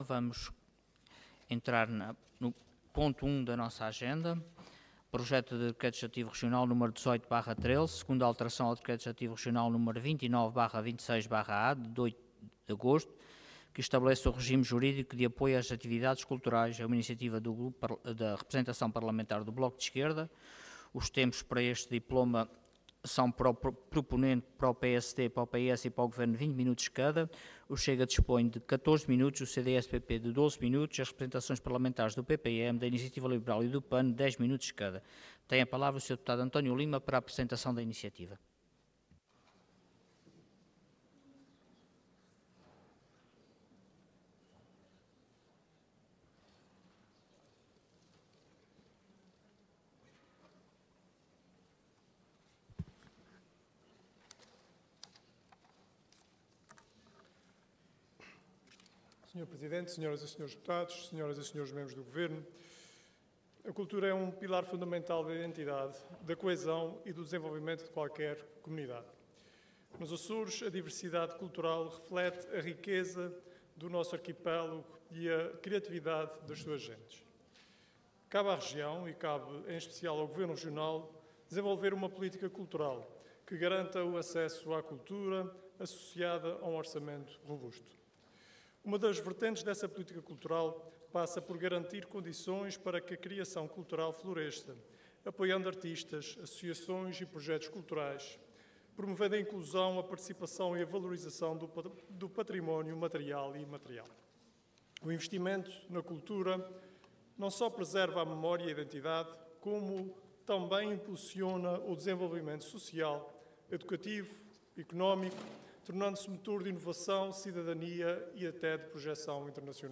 Intervenção
Orador António Lima Cargo Deputado Entidade BE